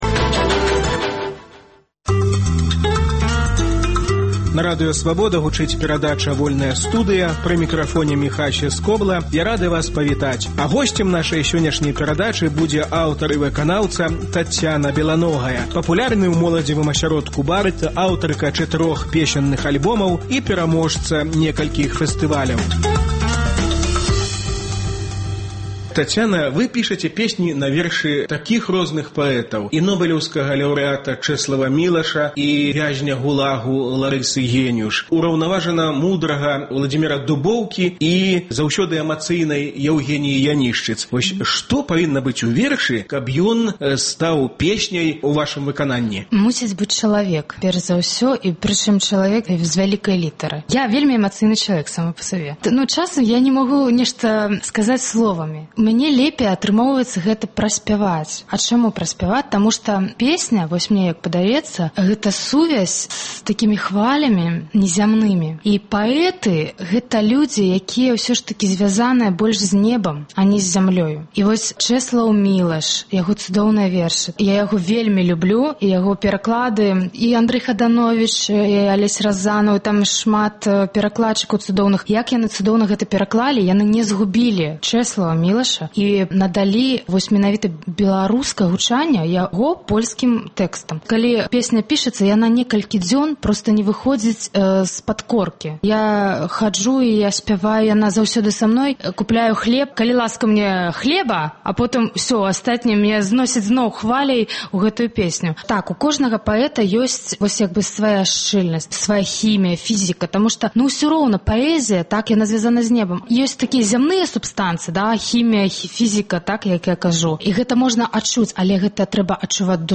Гутаркі без цэнзуры зь дзеячамі культуры й навукі.